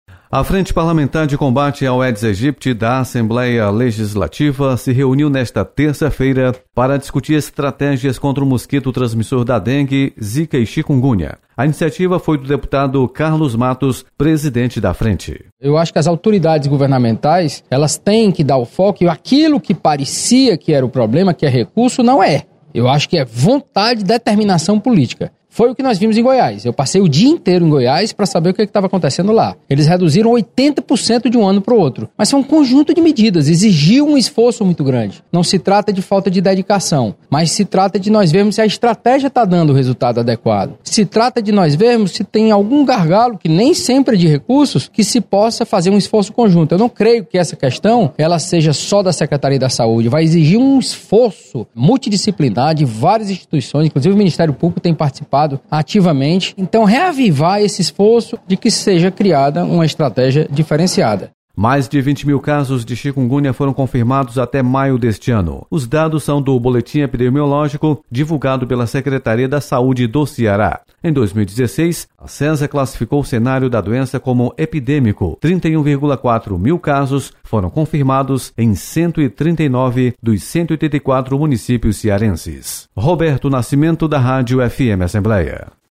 Frente discute combate ao mosquito Aedes aegypti. Repórter